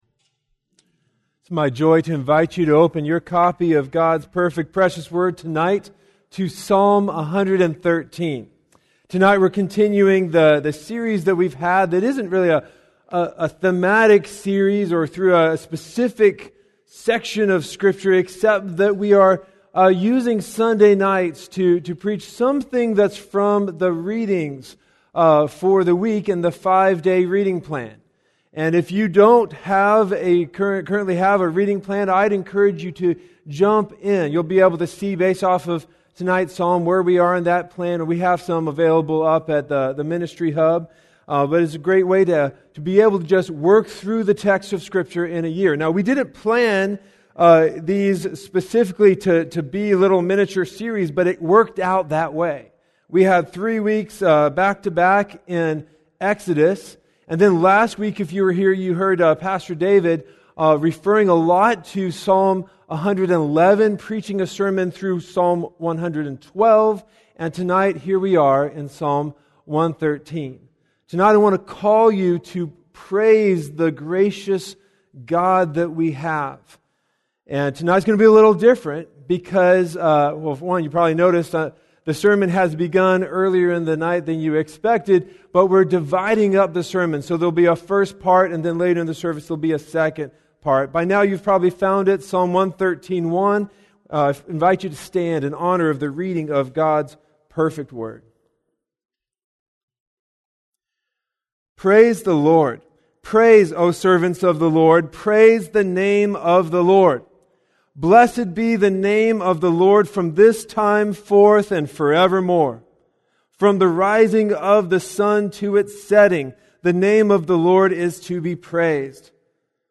View Larger Image A Call to Praise the Gracious God (Psalm 113) Sermon Audio Sermon Notes Sermon Audio http